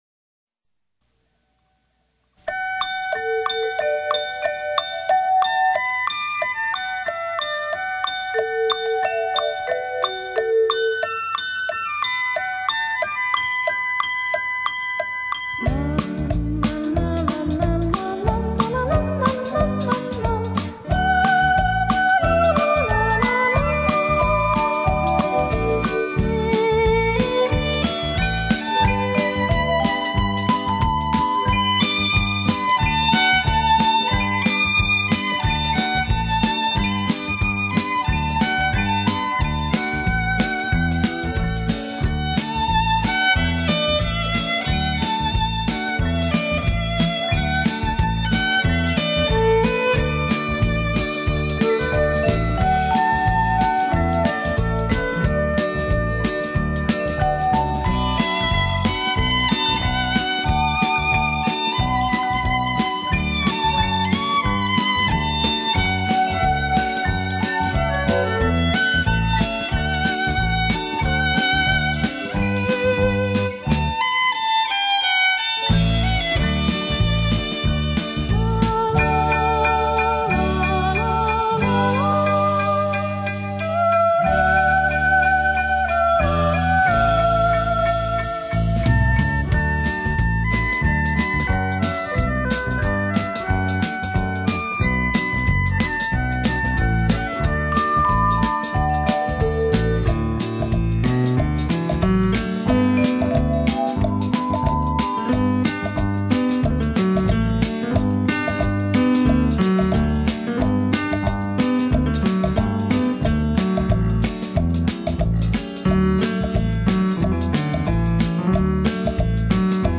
浪漫的萨克斯，
轻柔的钢琴，
聆听清雅怡人的音乐，
深情不腻的旋律，